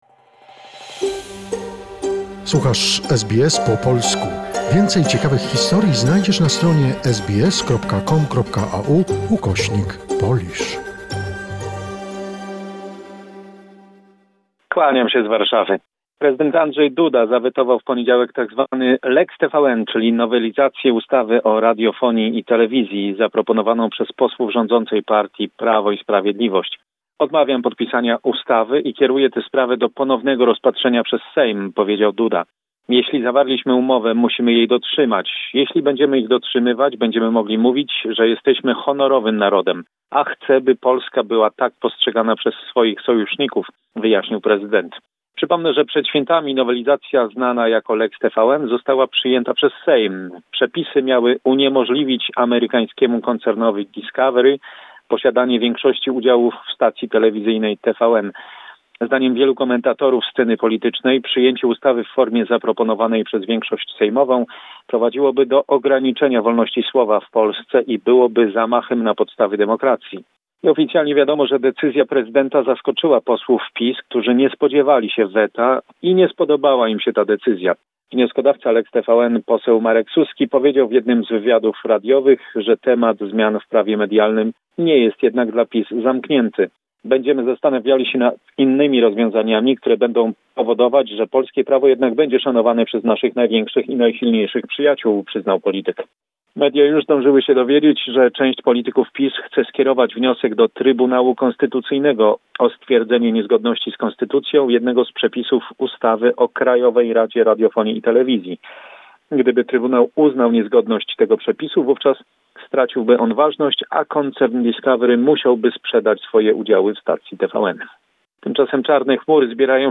Summary of the important events in Poland. Report